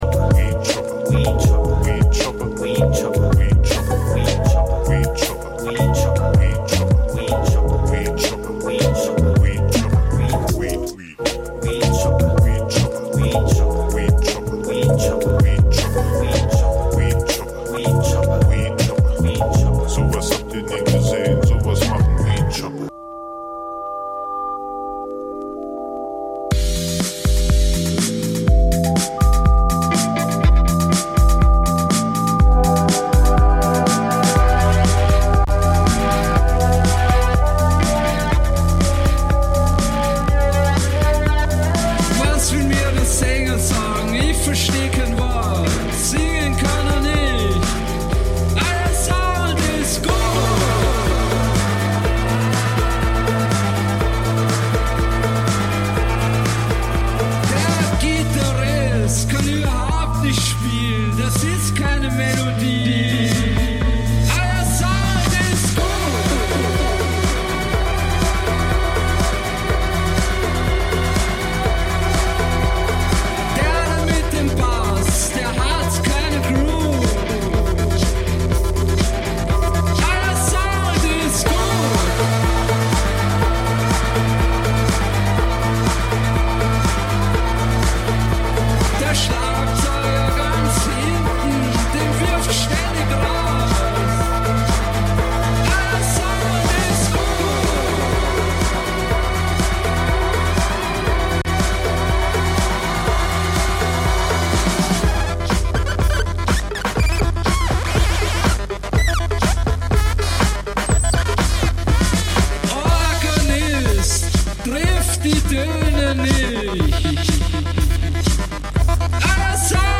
Musik vergangener Tage Dein Browser kann kein HTML5-Audio.
Schr�ge Originale stehen noch schr�geren Coverversionen gegen�ber.